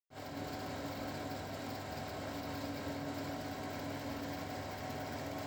To je zvuk toho v�tr�ku � podobn� krbov� vlo�ka po zhruba p�ti letech za�ala takto rezonovat a ned� se s t�m v�bec nic d�lat.